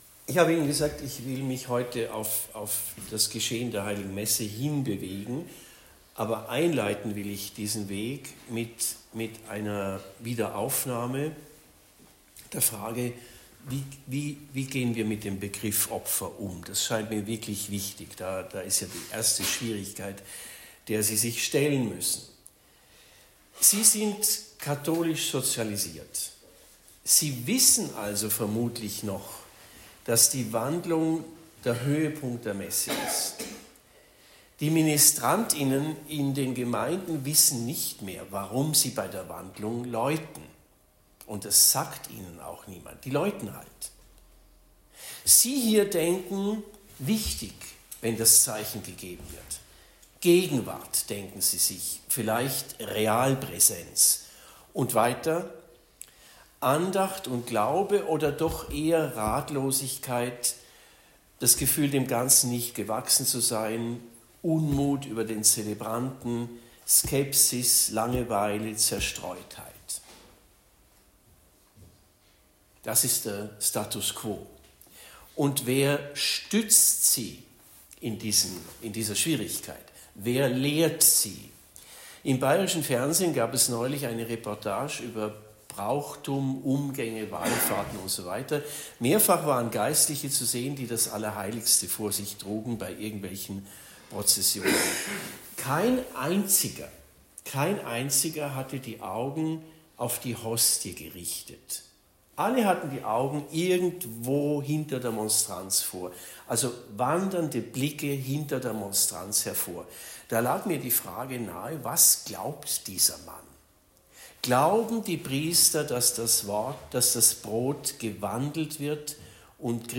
Hingabe und Wandlung Vortrag bei den Ordensexerzitien in Stift Schlägl, 07. bis 09. März 2025